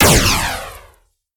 alien_laser_01.ogg